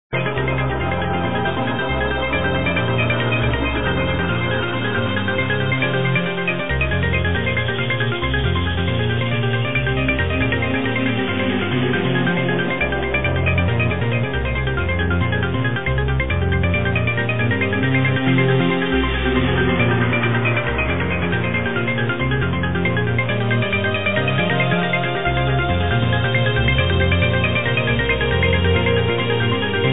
muzyka elektroniczna, ambient